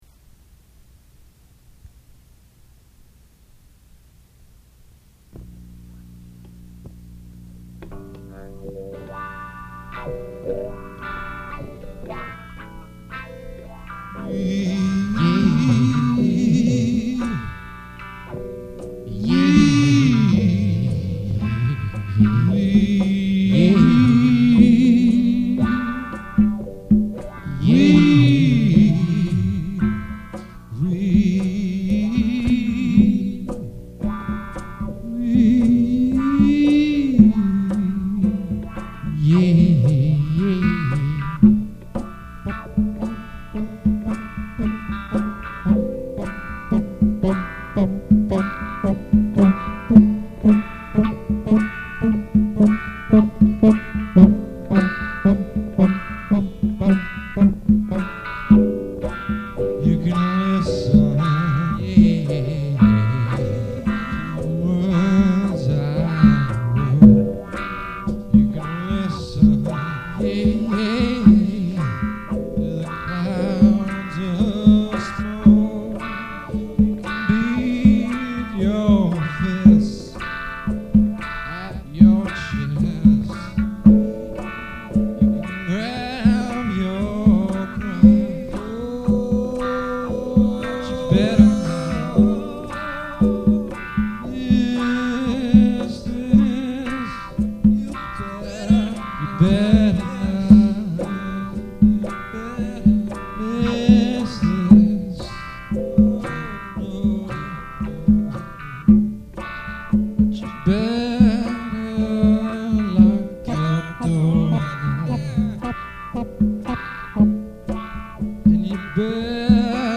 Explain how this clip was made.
ultra-lofi